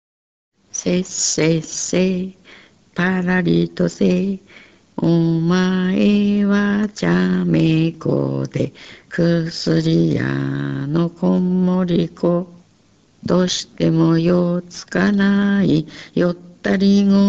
가사